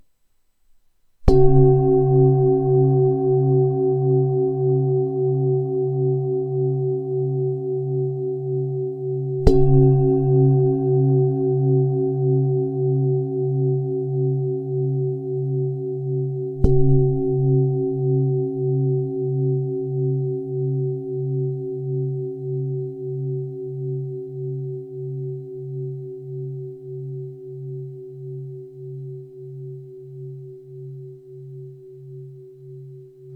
Ramgondi tibetská mísa C3 25,5cm
Mají hluboký zvuk a nejvíce se podobají zvonům.
Nahrávka mísy úderovou paličkou:
Mísa je však velice oblíbená i díky svému hlubšímu zvuku, který vybízí k relaxaci, odpočinku a ukotvení v tady a teď.
Její tajemný zvuk vás vtáhne do meditačního světa plného klidu a mystiky.
Jde o ručně tepanou tibetskou zpívající mísu dovezenou z Indie.